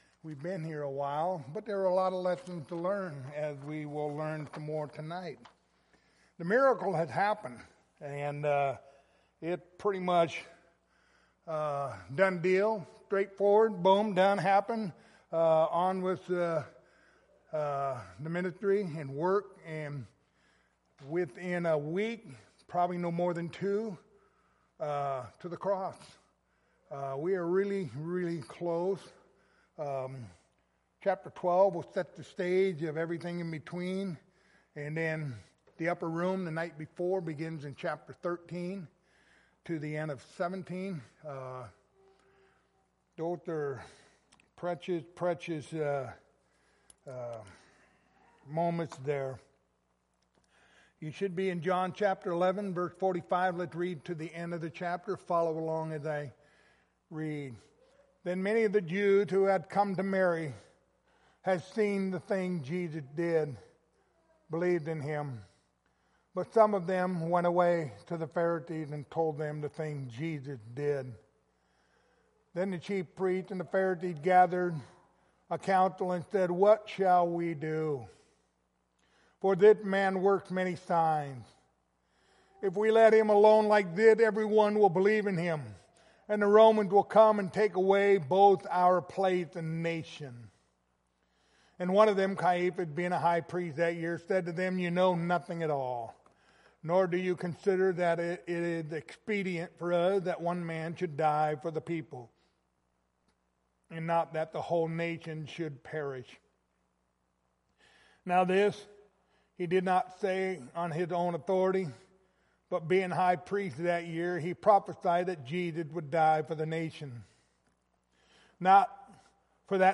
Passage: John 11:45-57 Service Type: Wednesday Evening